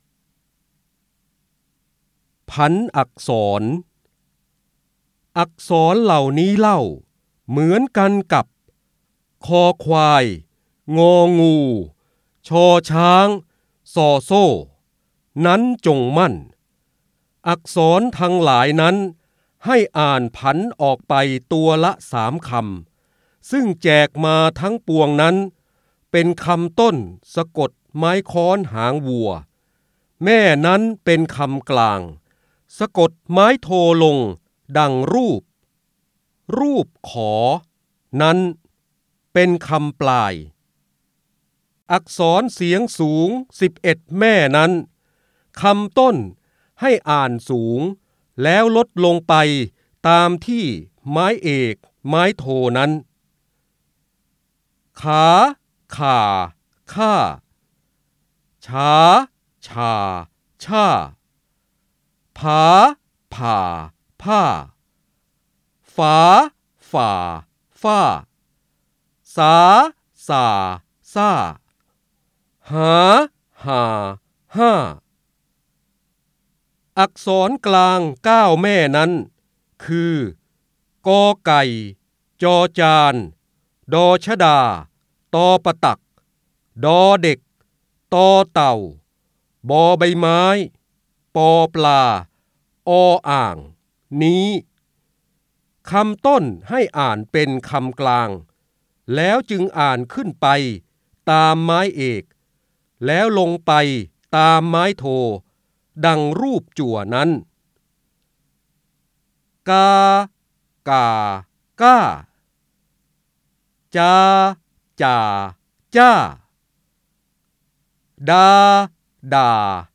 70 67 ตัวอย่าง ดาวน์โหลด ส่ง eCard สียงบรรยายจากหนังสือ จินดามณี (พระโหราธิบดี) ผันอักษร ได้รับใบอนุญาตภายใต้ ให้เผยแพร่-โดยต้องระบุที่มาแต่ห้ามดัดแปลงและห้ามใช้เพื่อการค้า 3.0 Thailand .